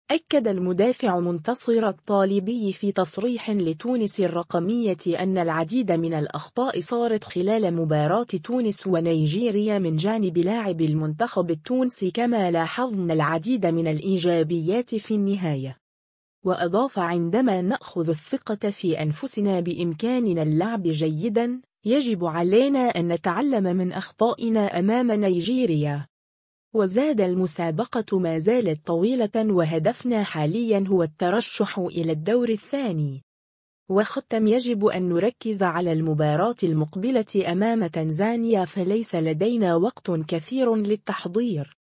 أكّد المدافع منتصر الطالبي في تصريح لتونس الرقمية أنّ العديد من الأخطاء صارت خلال مباراة تونس و نيجيريا من جانب لاعبي المنتخب التونسي كما لاحظنا العديد من الإيجابيات في النهاية.